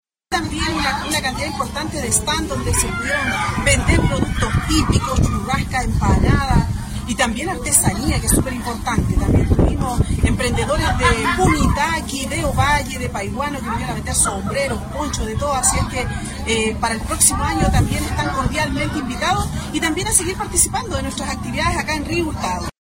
La alcaldesa Carmen Juana Olivares agradecieron a la comunidad, emprendedores y artistas por ser parte de este encuentro que refuerza nuestra identidad y tradiciones.